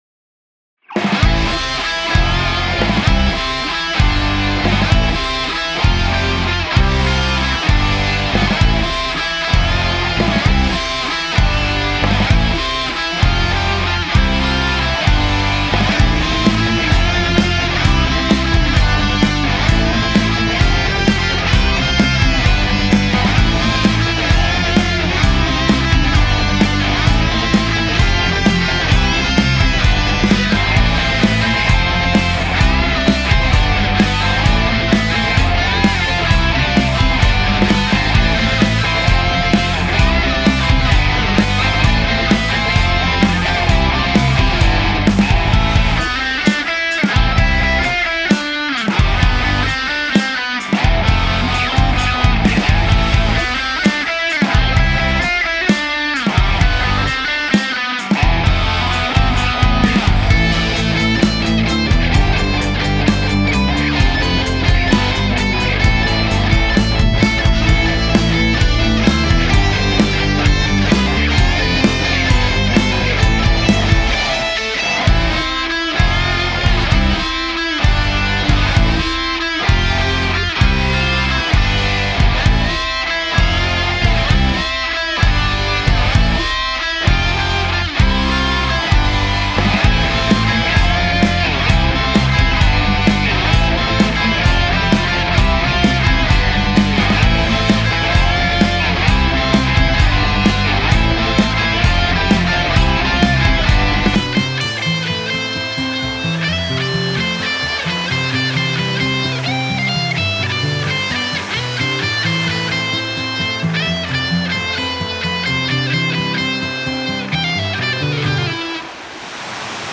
Glinka-More-zovet-gitarnyy-instrumental-stih-club-ru.mp3